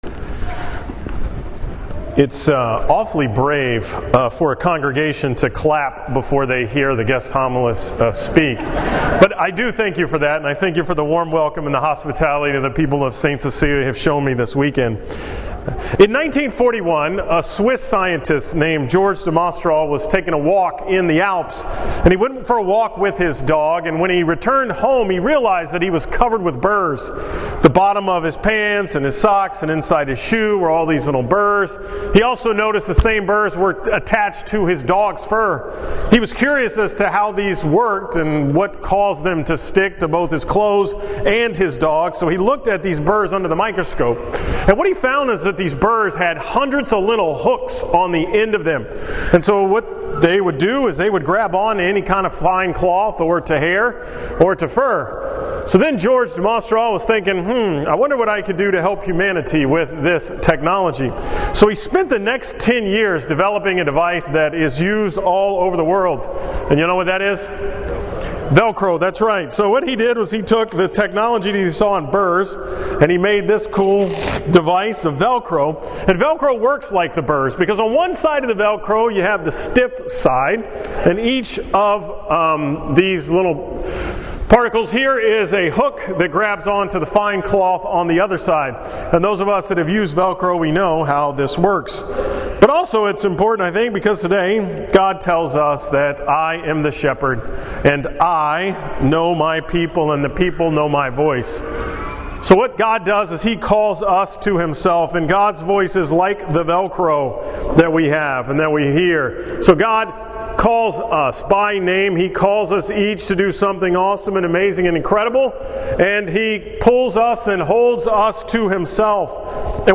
From Mass at St. Cecilia's on Sunday, April 17th